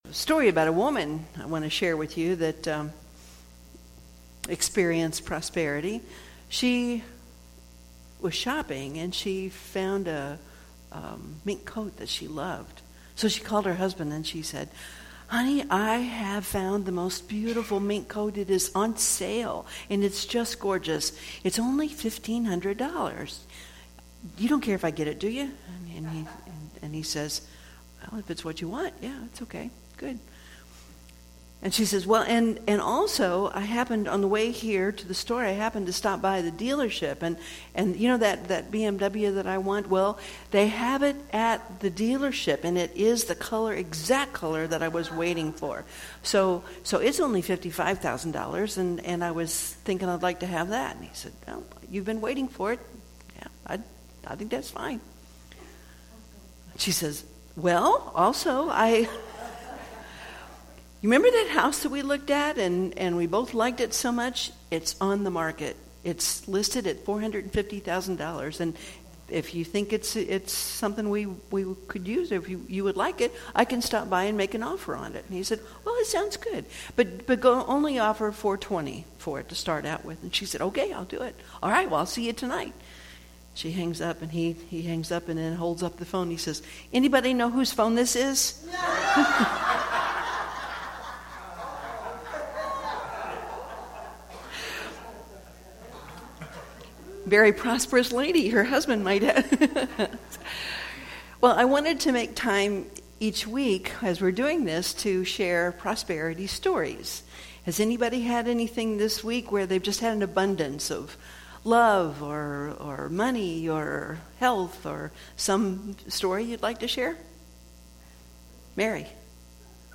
Series: Sermons 2016